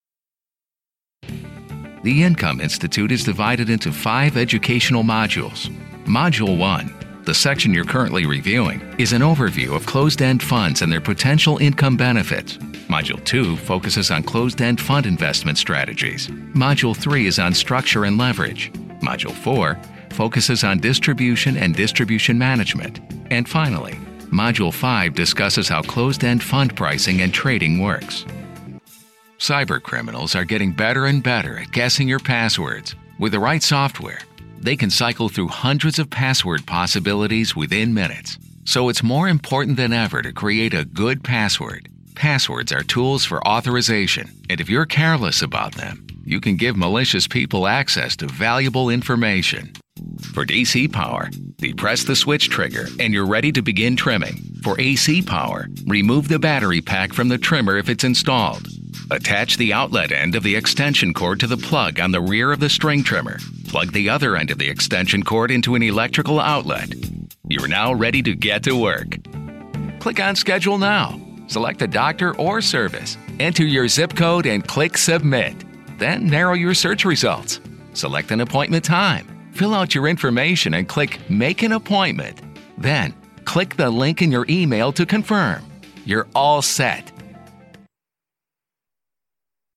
Male
American English (Native)
Authoritative, Confident, Cool, Deep, Engaging, Friendly, Gravitas, Natural, Smooth, Warm
Promo.mp3
Microphone: Neumann TLM -49